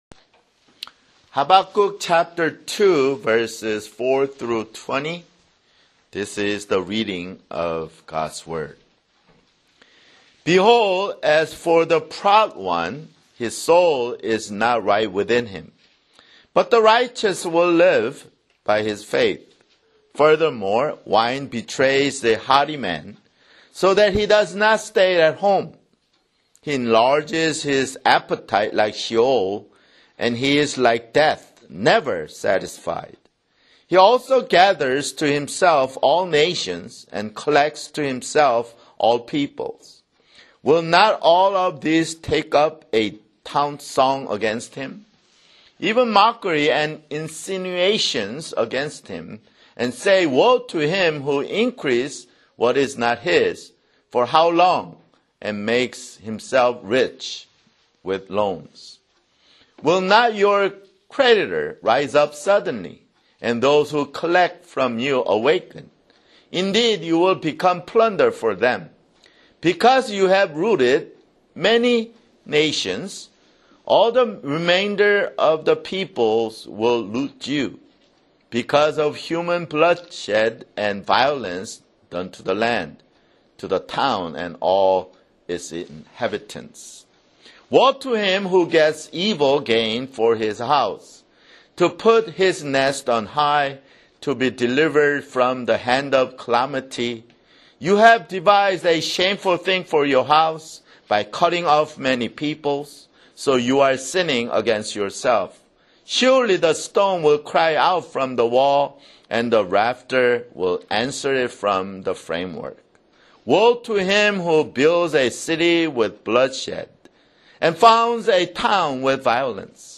Sunday, May 18, 2014 [Sermon] Habakkuk (10) Habakkuk 2:4-20